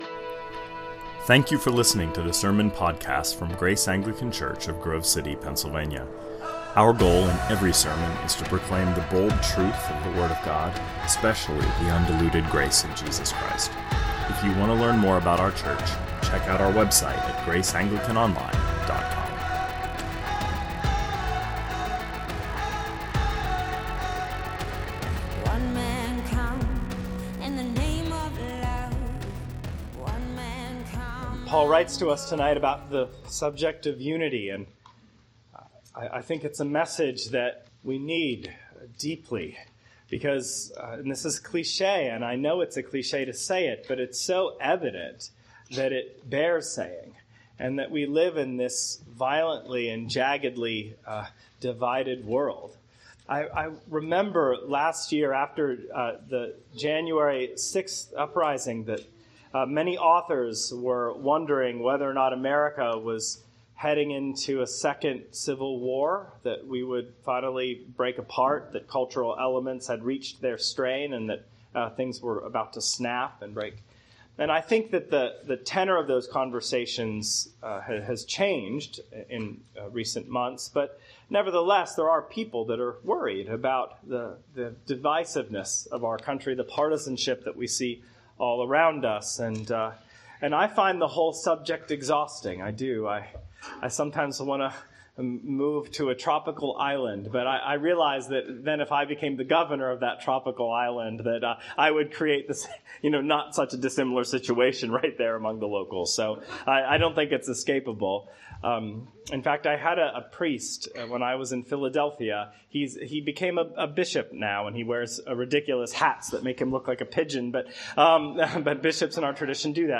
2022 Sermons